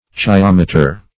Search Result for " chyometer" : The Collaborative International Dictionary of English v.0.48: Chyometer \Chy*om"e*ter\, n. [Gr.